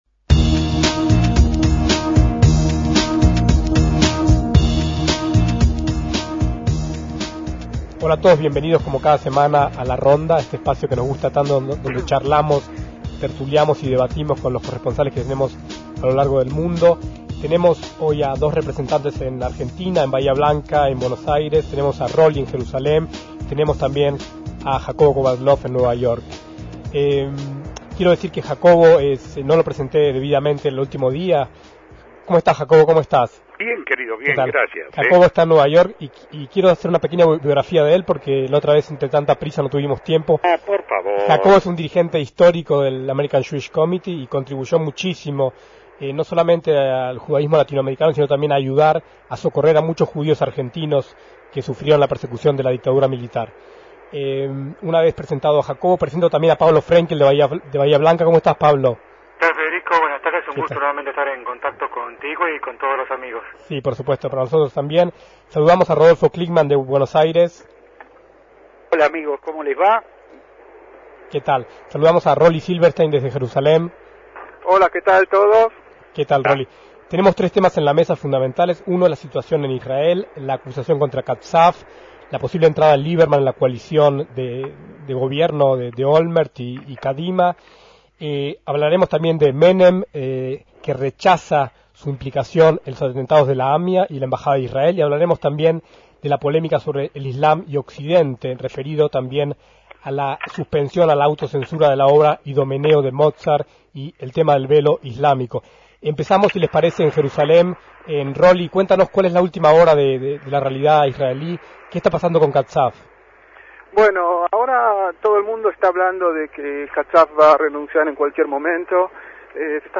Una nueva ronda de corresponsales